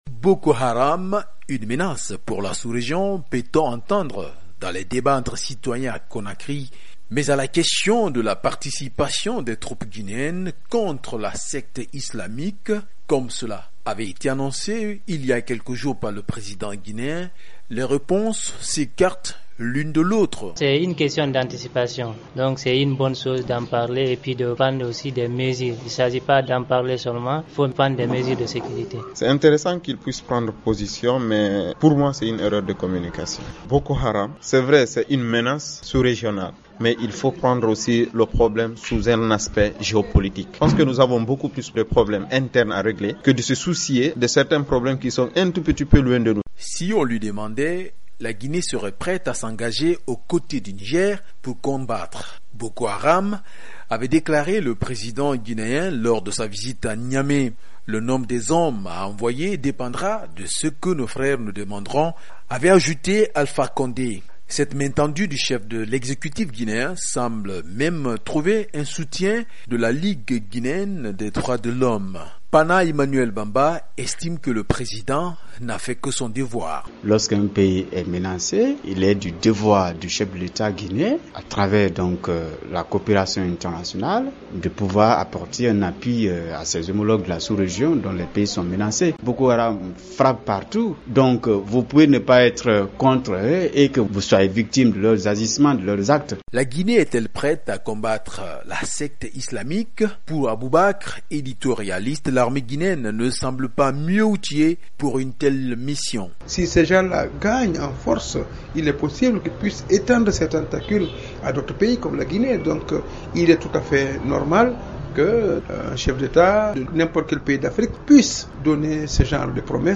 Dans les rues de Conakry, si certains estiment qu'il faut réagir à la menace que posent les djihadistes, d'autres pensent que le pays a des problèmes plus pressants.
Reportage